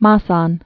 (mäsän)